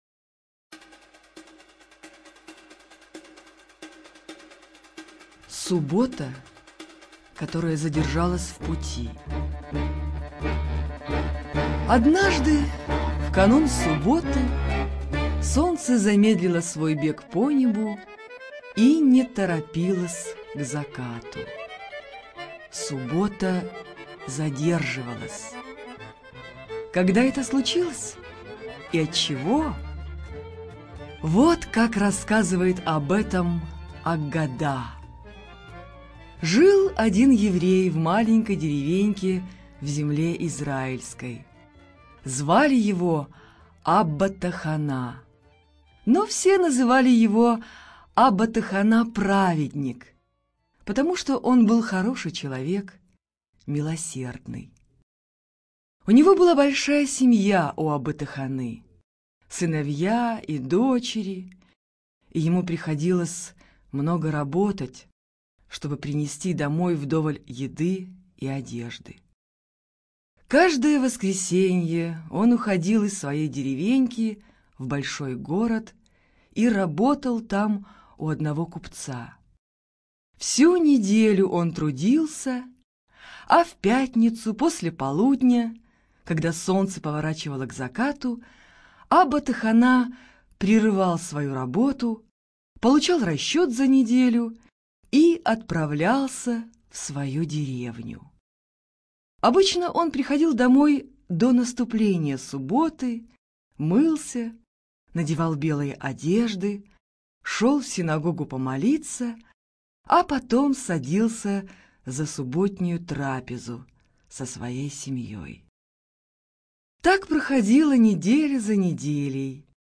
ЖанрДетская литература, Сказки